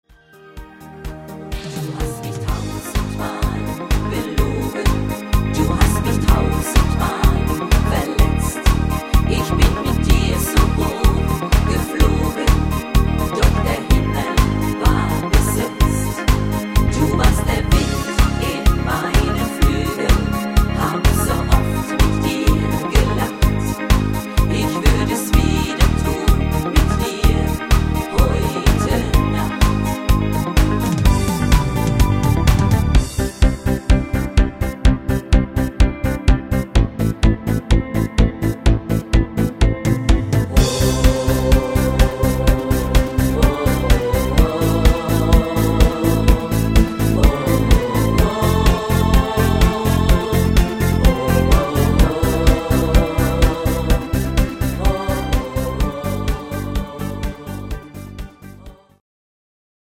gut zu singende Tonarten